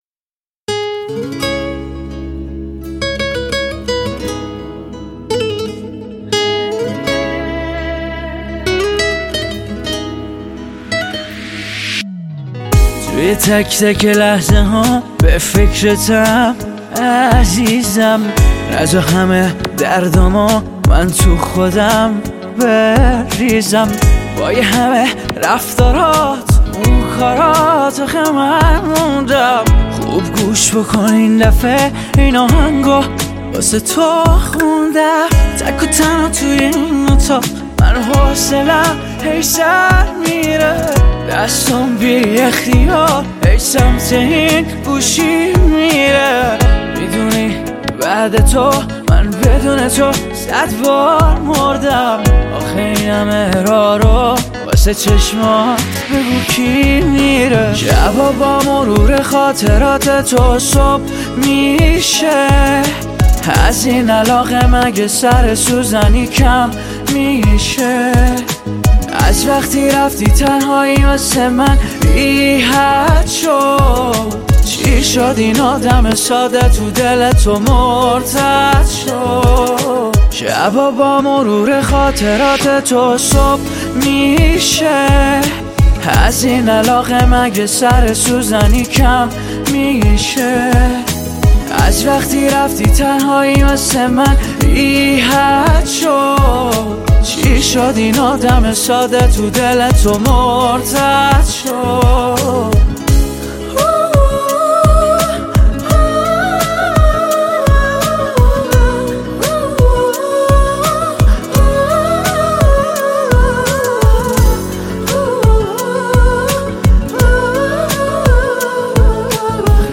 شاد و عاشقانه